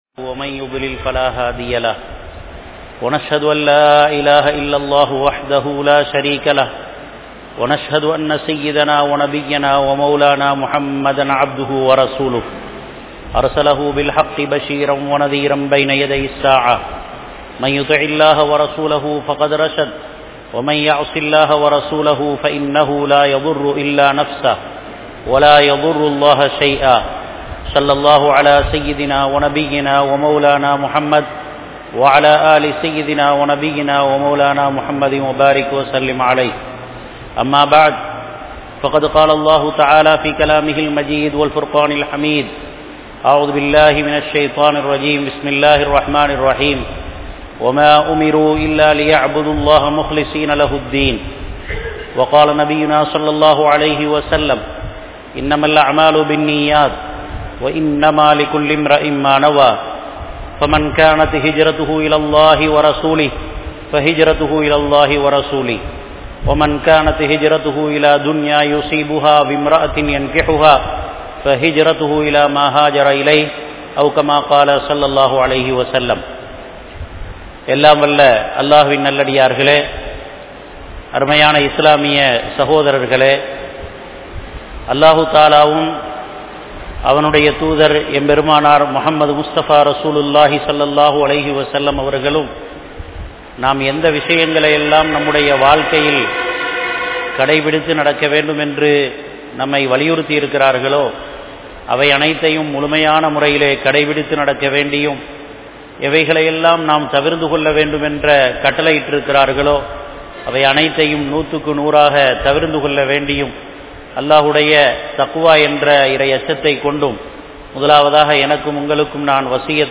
Maraimuhamaana Inai Vaippu (மறைமுகமான இணைவைப்பு) | Audio Bayans | All Ceylon Muslim Youth Community | Addalaichenai